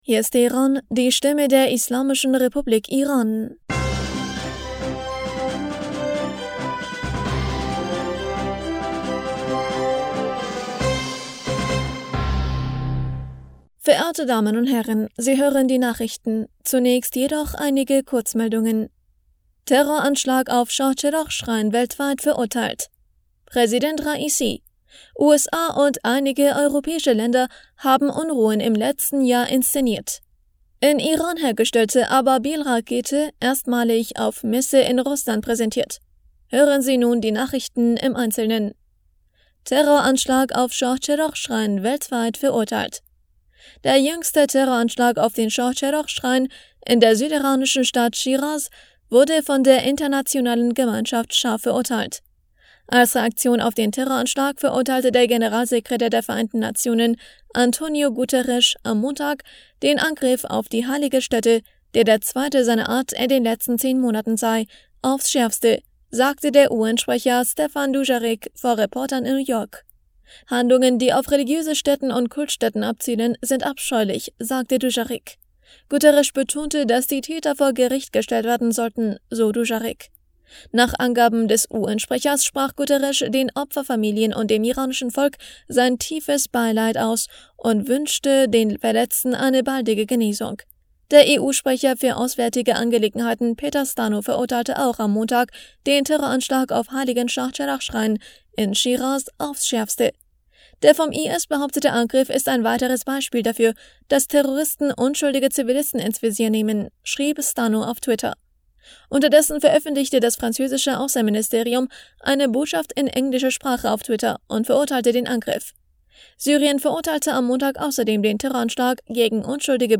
Nachrichten vom 15. August 2023